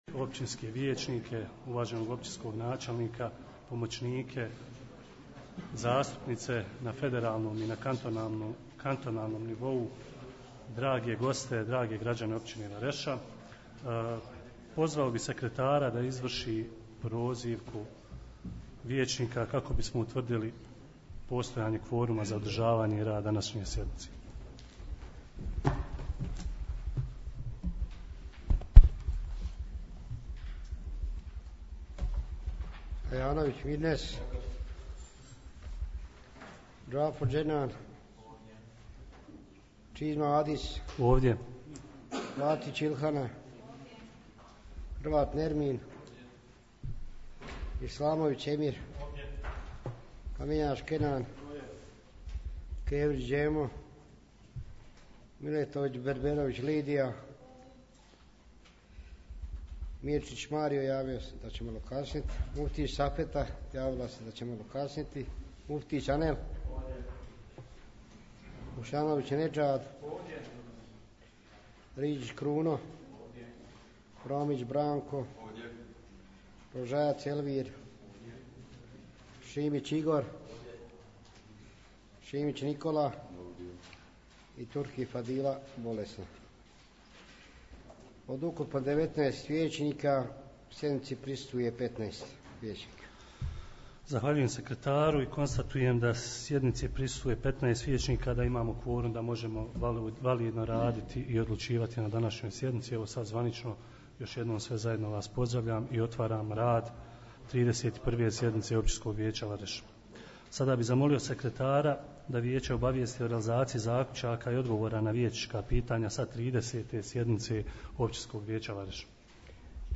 31. sjednica Općinskog vijeća Vareš